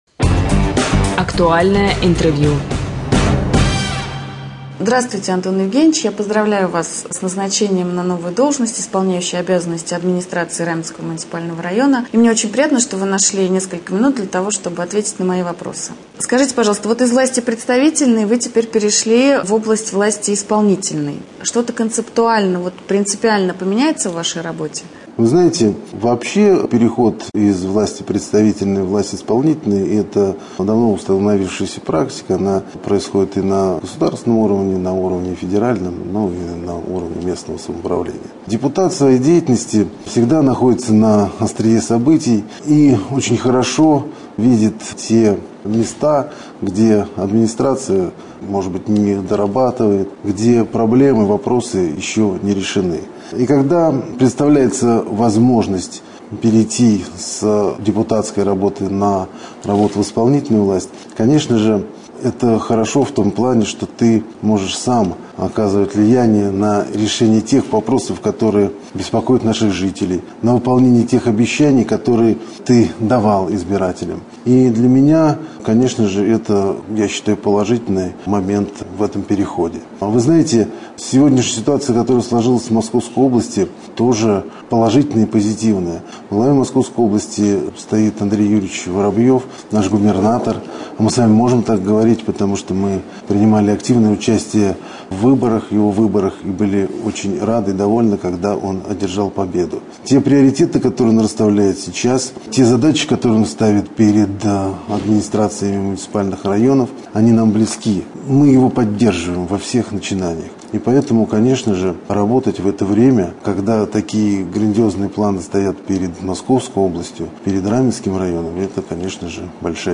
1.Рубрика «Актуальное интервью».